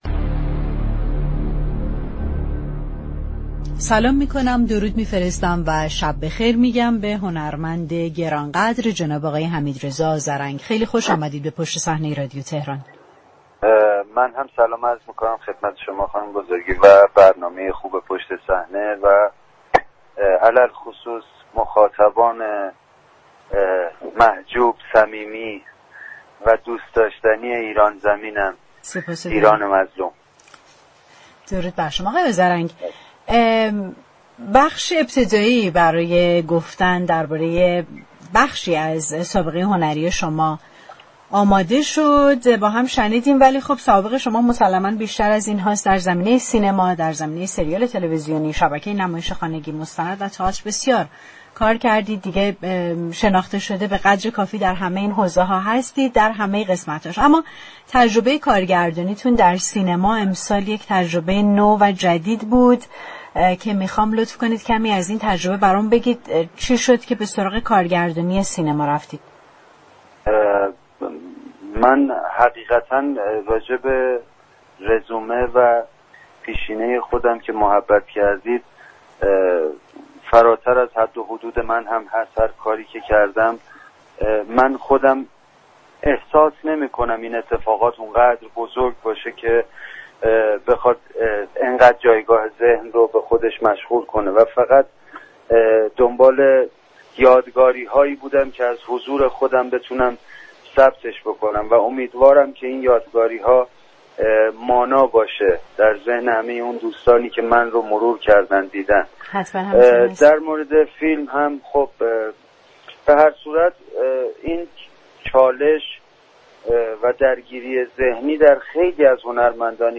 به گزارش پایگاه اطلاع رسانی رادیو تهران، حمیدرضا آذرنگ، بازیگر، نویسنده و كارگردان، مهمان تلفنی یكشنبه 26 بهمن ماه برنامه پشت صحنه رادیو تهران درباره بخش معرفی خود در این برنامه گفت: درباره پیشینه من كه گفته شد، فراتر از حد و حدود من است.